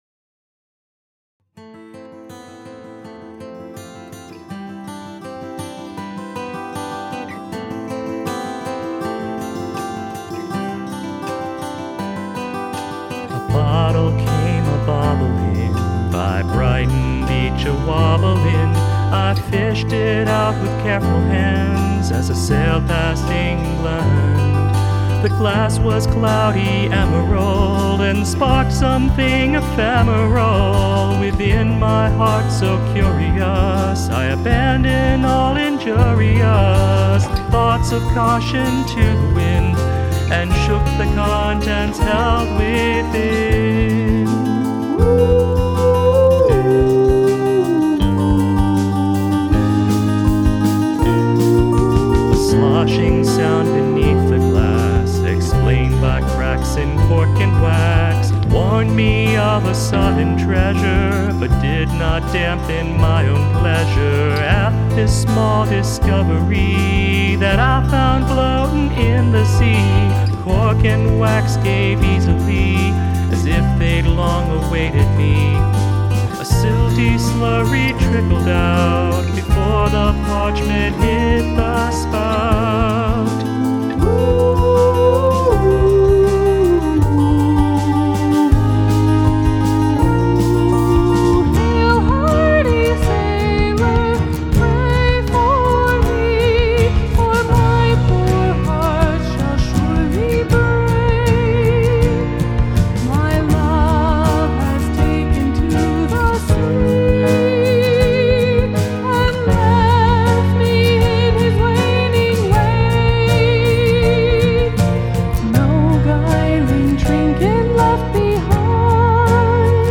This could stand on the rhythm of the acoustic guitars.